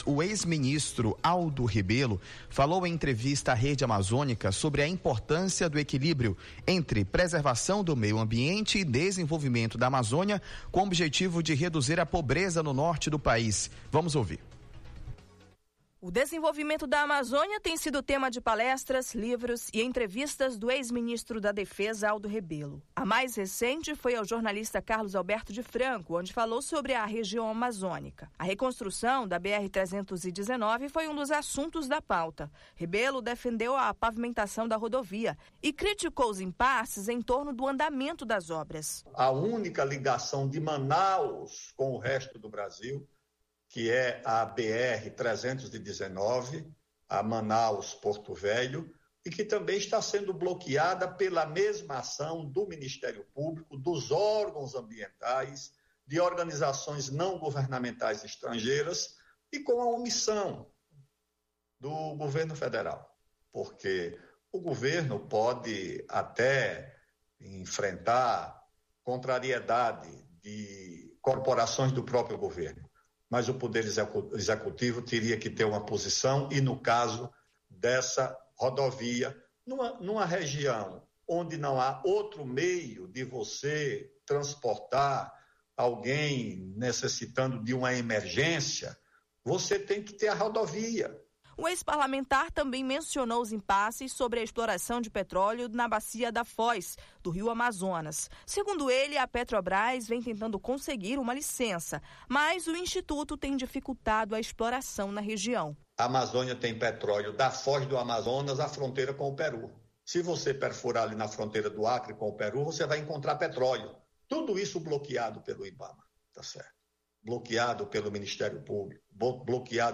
Ex-ministro concedeu entrevista e falou sobre objetivos de reduzir a pobreza no norte do país